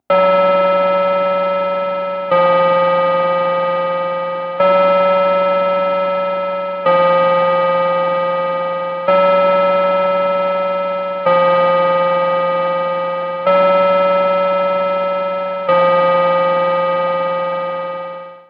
不思議系効果音です。
ディンドンディンドン・・・